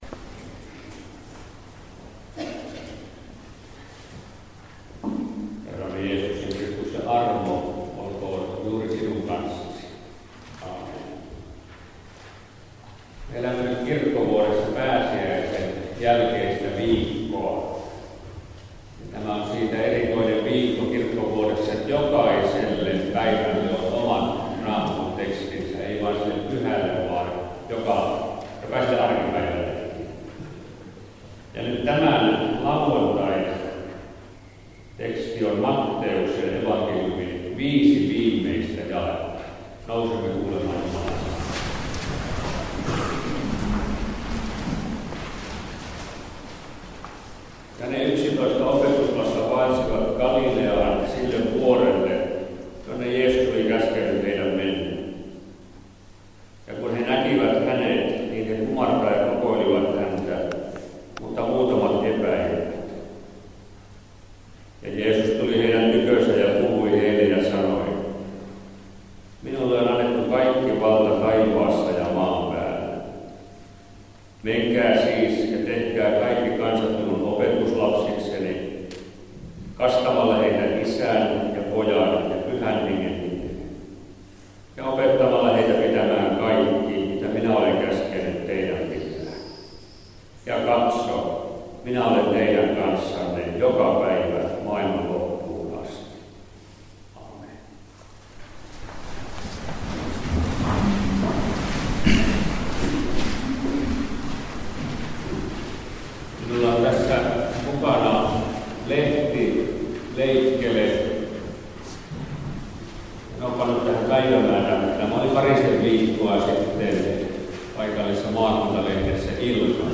Saarna SoiVappu-tapahtuman Lina Sandell-messussa Lapuan Kauhajärven kirkossa.